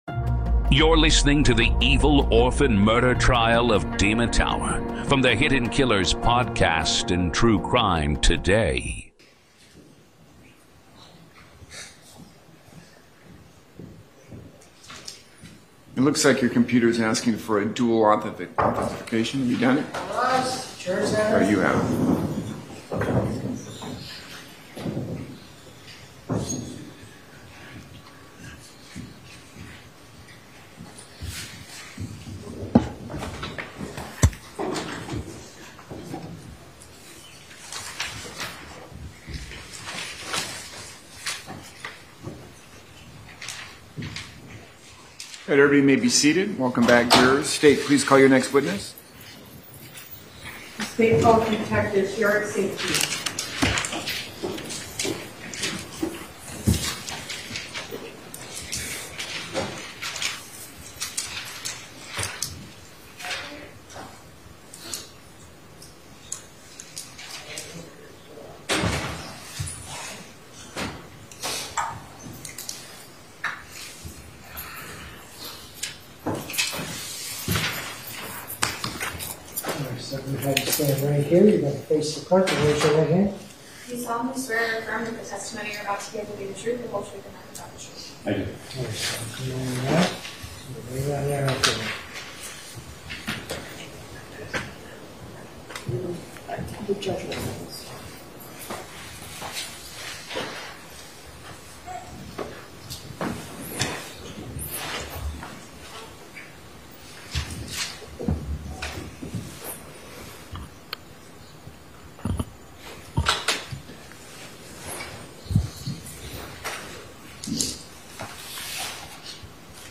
This episode features unedited courtroom audio
The defense claims years of trauma and emotional disturbance shaped what happened that night. In this ongoing Hidden Killers courtroom-coverage series, we present the raw sounds of justice — no commentary, no edits — just the voices of attorneys, witnesses, and the judge as the case unfolds in real time.